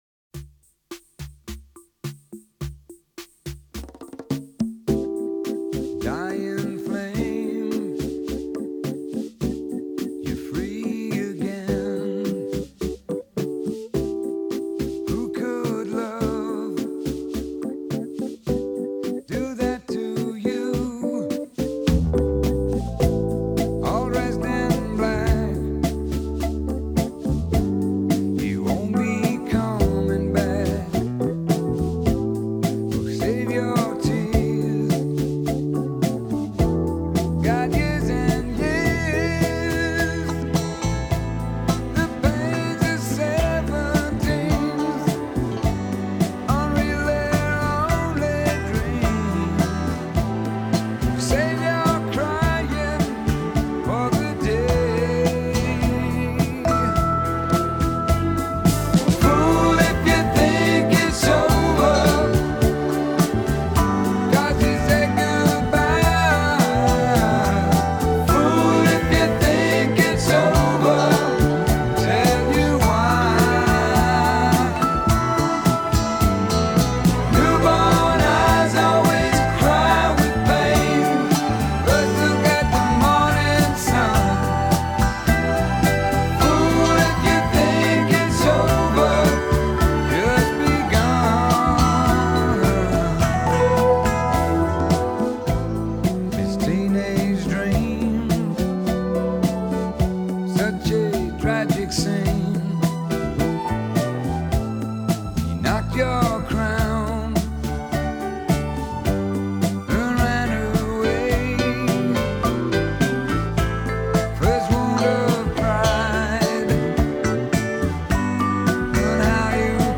un artista del pop-rock con su voz rota y su guitarra slide